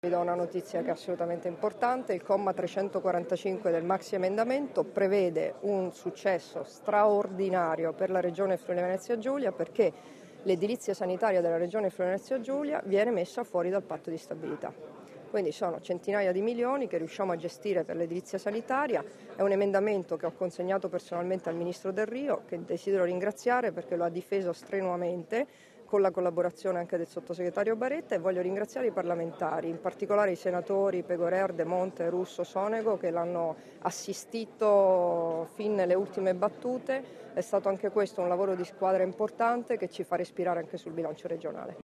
Dichiarazioni di Debora Serracchiani (Formato MP3) [671KB]